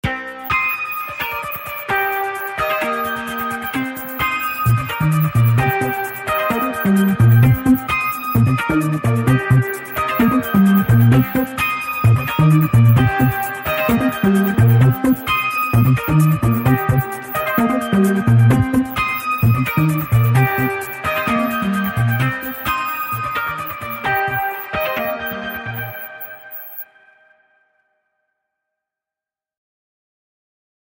Kategorie Efekty Dźwiękowe